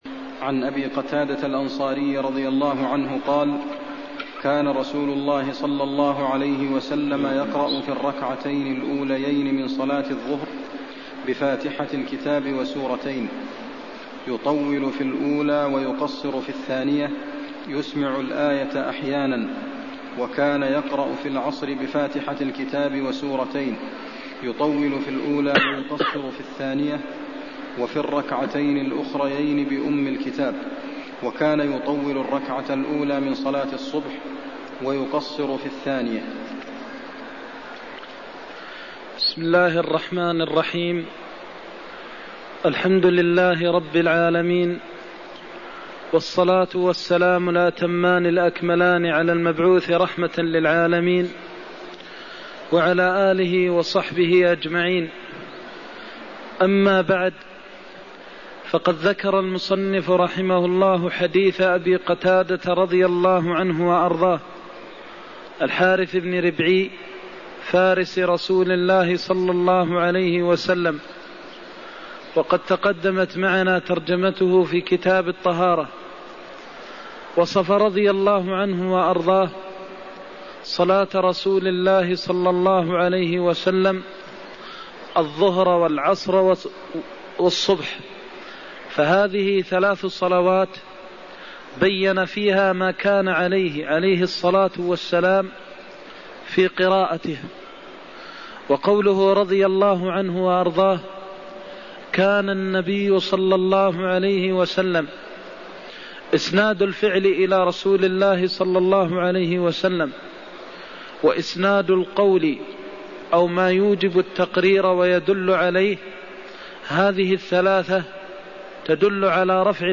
المكان: المسجد النبوي الشيخ: فضيلة الشيخ د. محمد بن محمد المختار فضيلة الشيخ د. محمد بن محمد المختار كان الرسول يقرأفي الركعتين الأوليين (94)